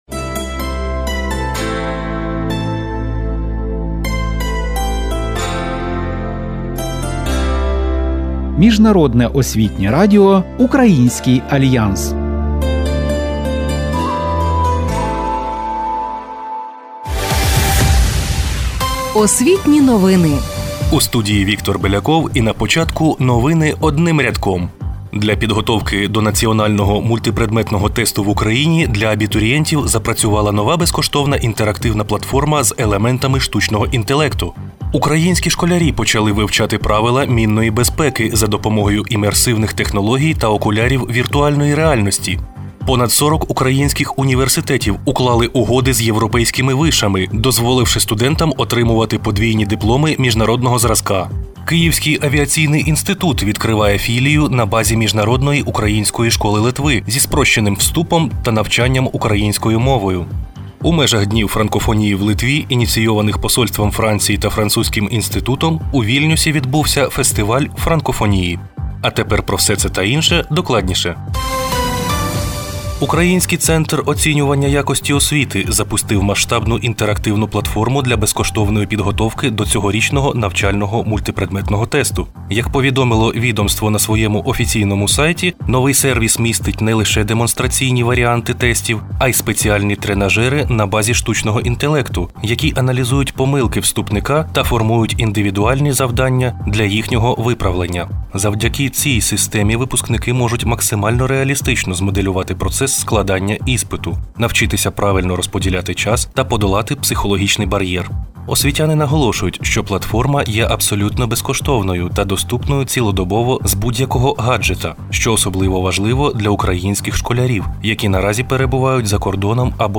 У програмі: інтерактивна платформа з ШІ для підготовки до НМТ, VR-технології у прифронтових школах, тріумф учнів МУШ Литви на фестивалі франкофонії у Вільнюсі, історичні паралелі 21 квітня (від Варшавського договору до виборів 2019-го), радіонарис про боротьбу легендарного Леоніда Бикова з радянською цензурою, практичний гайд з профорієнтації для тих, хто ще шукає себе, та сильна поезія Івана Франка.